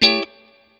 CHORD 7   AH.wav